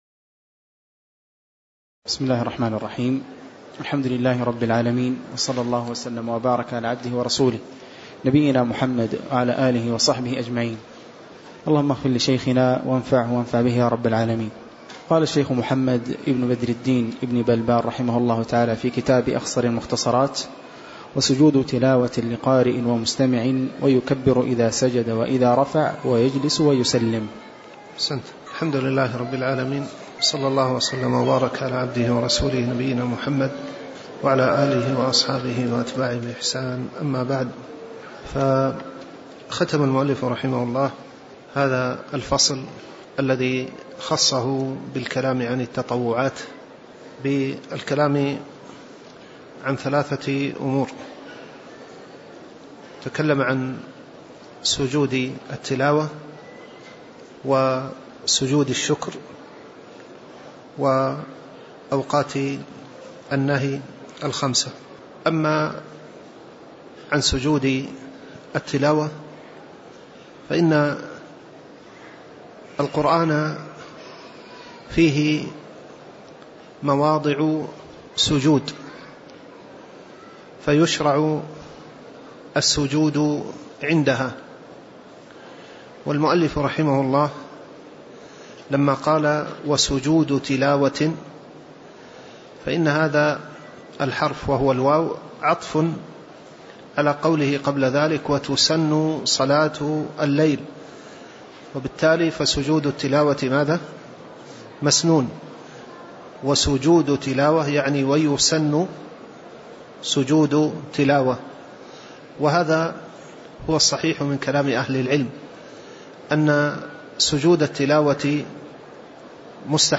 تاريخ النشر ٢٥ جمادى الآخرة ١٤٣٩ هـ المكان: المسجد النبوي الشيخ